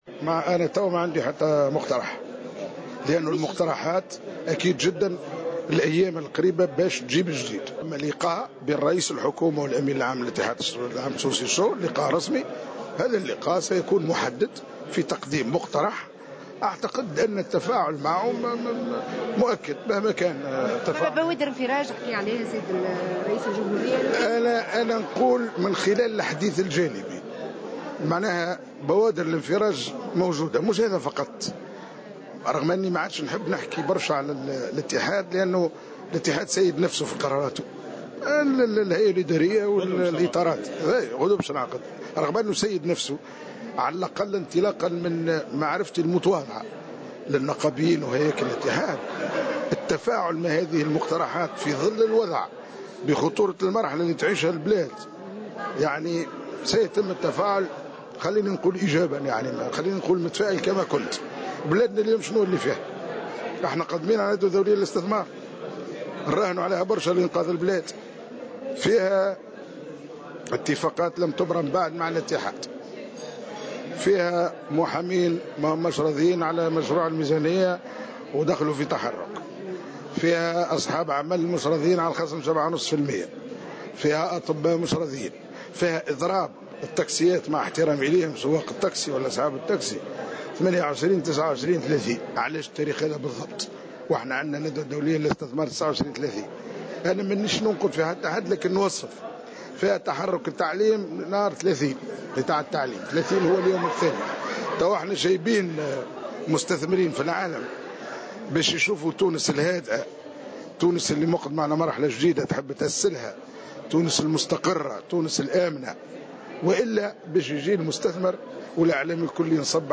أشار وزير الوظيفة العمومية والحوكمة، عبيد البريكي في تصريح اليوم لمراسلة "الجوهرة أف أم" إلى وجود بوادر انفراج بين الاتحاد العام التونسي للشغل والحكومة فيما يتعلّق بمسألة تأجيل الزيادة في الاجور.